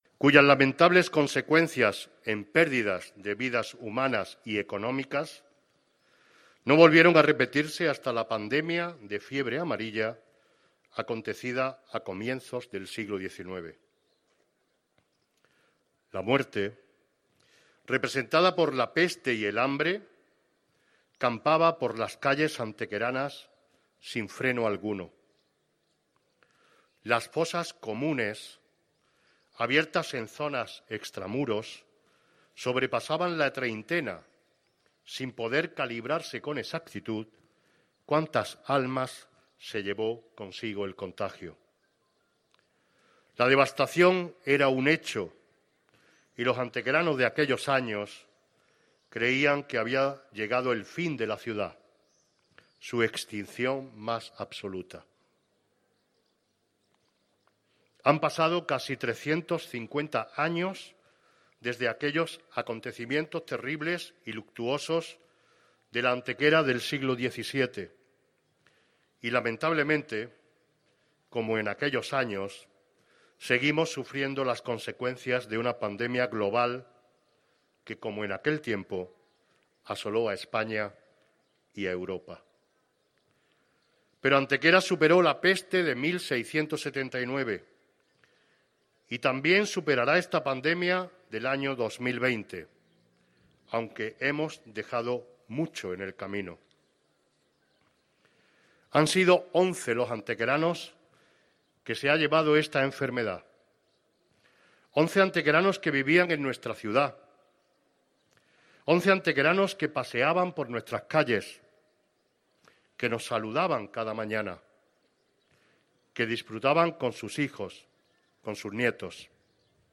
La céntrica confluencia de la plaza de la Constitución, la plaza de Castilla y el arco de Estepa ha acogido en la tarde de este viernes 5 de junio, coincidiendo con las últimas horas del luto oficial decretado para todo el país por el Gobierno de España, un sencillo pero a su vez emotivo homenaje a todas las víctimas del virus COVID-19, que en el caso de nuestro municipio han sido cifradas en once hasta el momento.
Reproducción íntegra del manifiesto leído por el Alcalde de Antequera
Cortes de voz